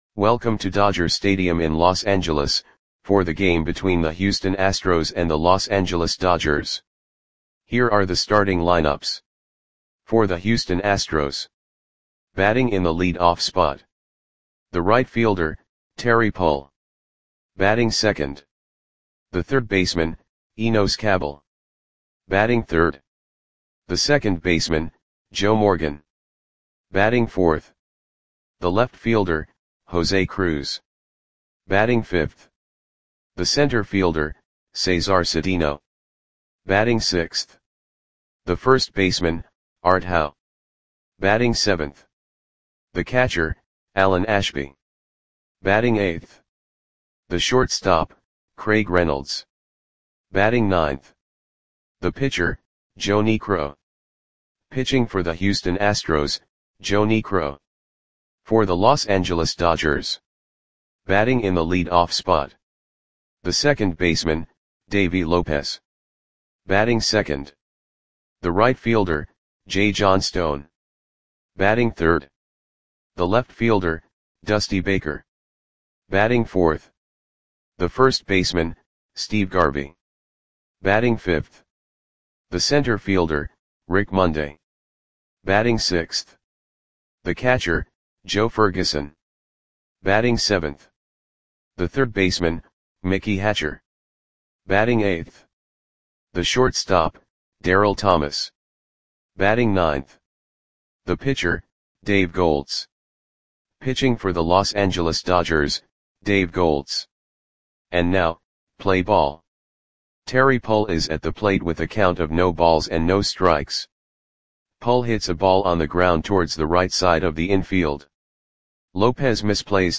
Audio Play-by-Play for Los Angeles Dodgers on October 6, 1980
Click the button below to listen to the audio play-by-play.